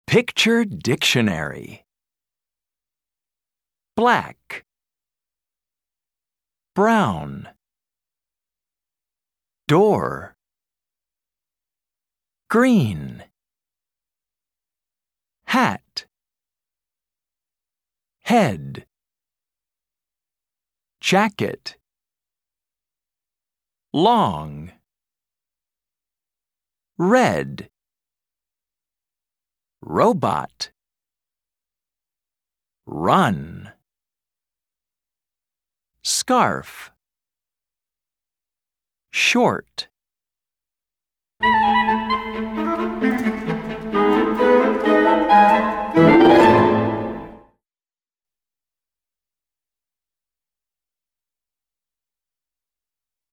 Track 6 Where's My Hat US English.mp3